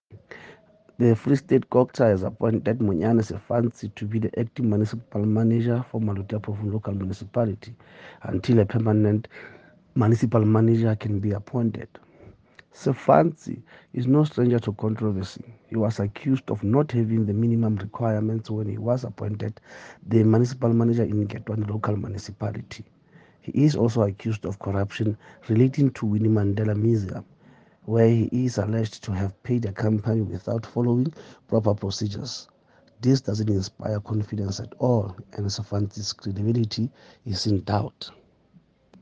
Sesotho soundbites by Cllr Moshe Lefuma.